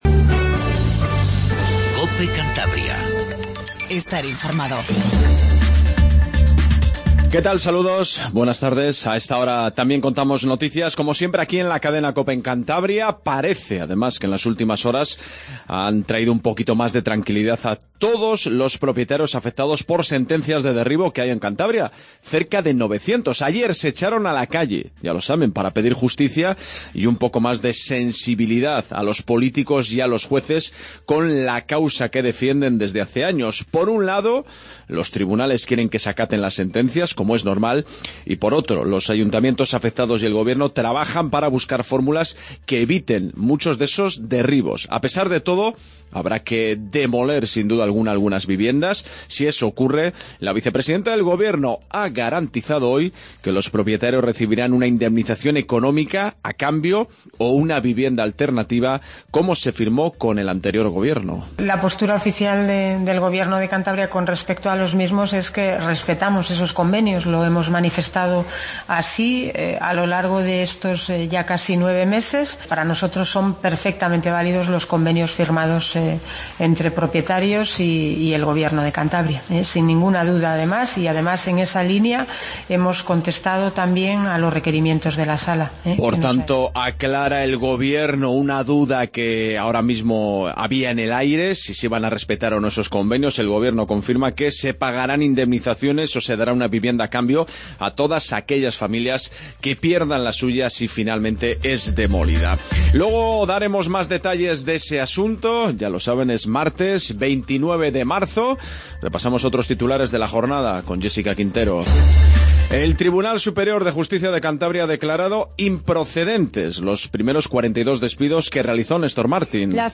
INFORMATIVO REGIONAL 14:10